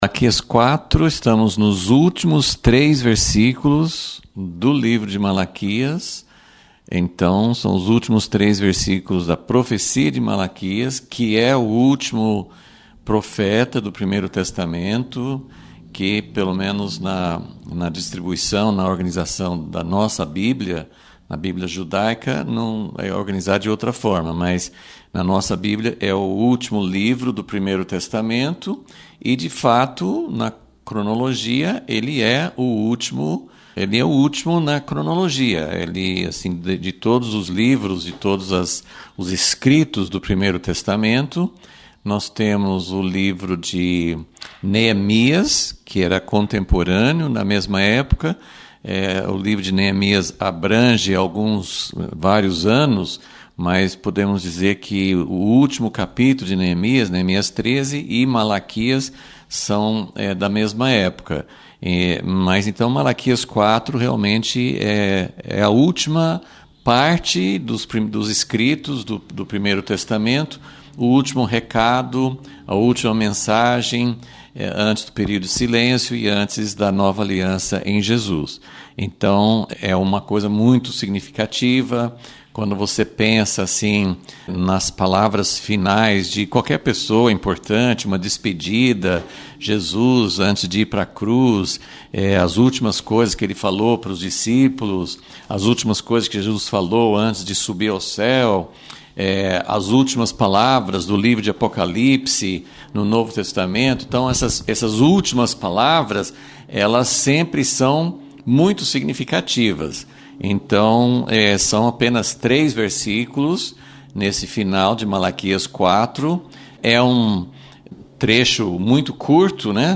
Aula 26 – Vol. 37 – As duas partes da última mensagem de Malaquias